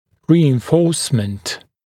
[ˌriːɪn’fɔːsmənt][ˌри:ин’фо:смэнт]укрепление, усиление